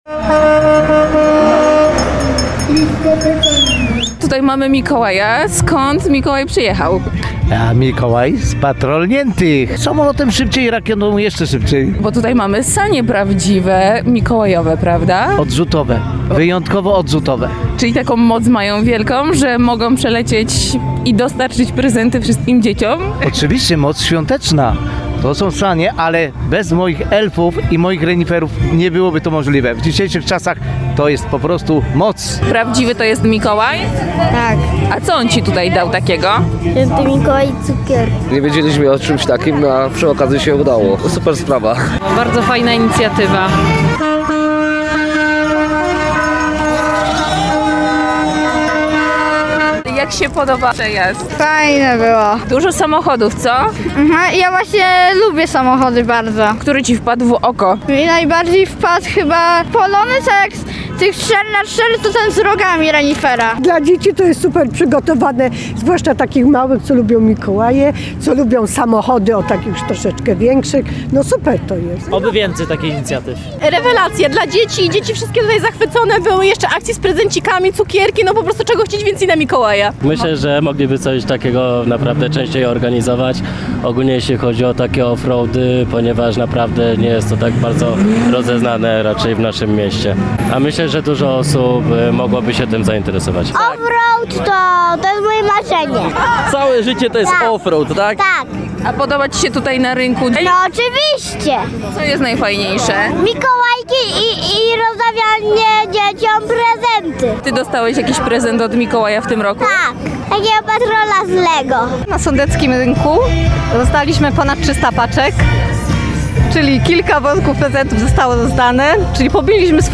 Wszystko rozpoczęło się pod ratuszem, a później auta i jednoślady z mikołajkowymi akcentami można było zobaczyć na wielu ulicach miasta.
Warkot silników i trąbienie klaksonów towarzyszyły przejazdowi Motomikołajów przez Nowy Sącz.